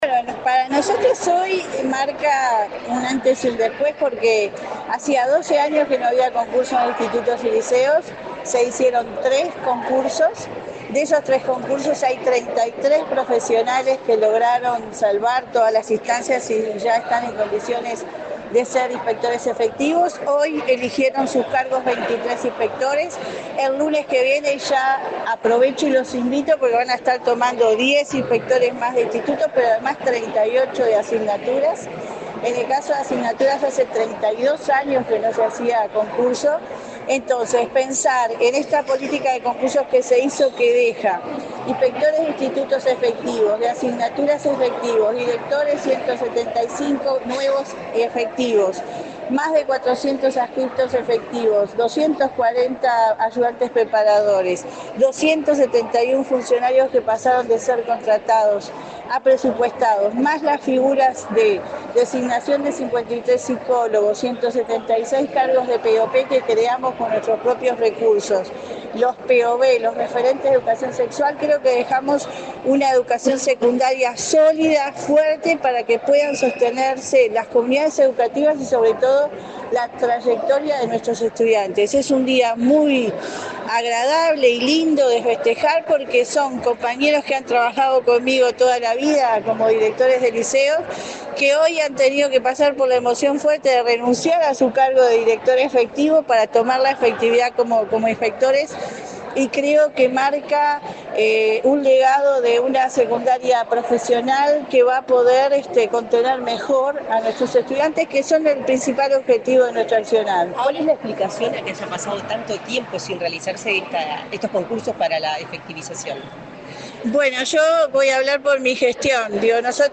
Declaraciones de la directora de Secundaria, Jenifer Cherro
La directora general de Educación Secundaria, Jenifer Cherro, dialogó con la prensa, luego de participar en el acto de incorporación de 33 inspectores